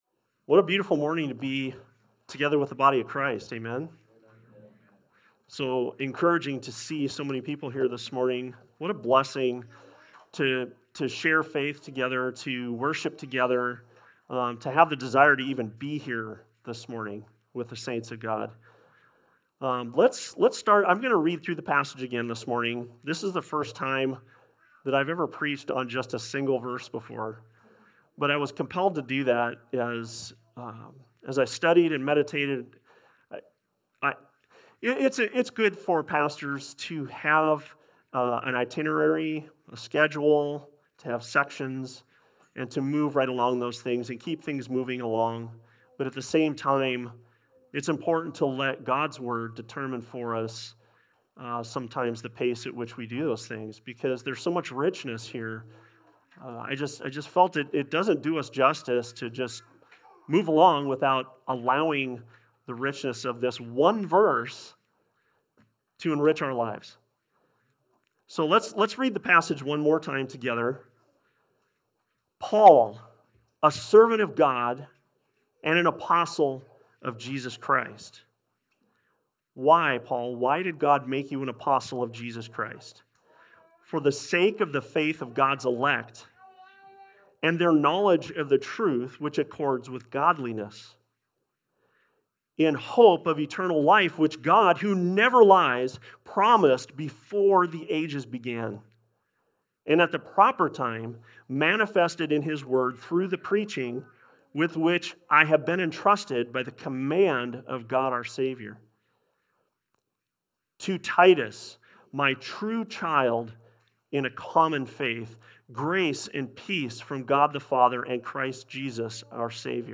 Titus 1:5 Service Type: Sunday Service « “An Introduction to Titus